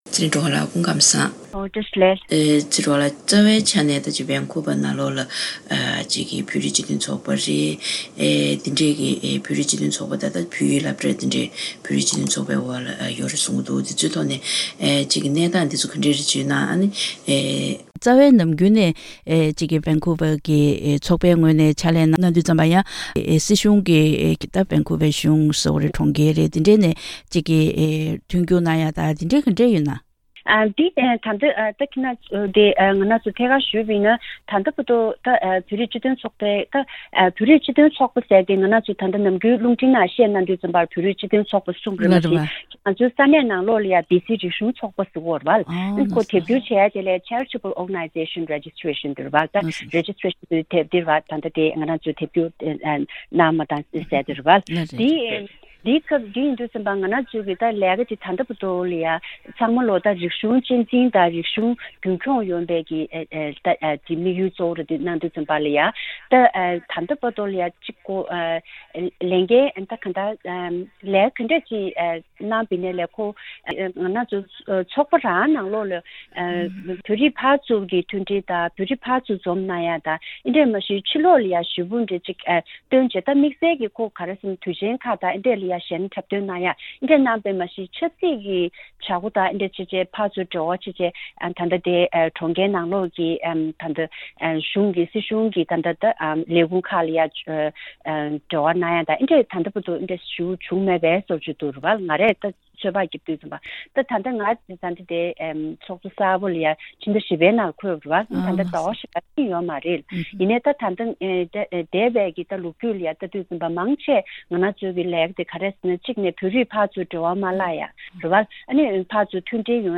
གནས་དྲི་ཞུས་པ་ཞིག་གསན་རོགས་གནང་།།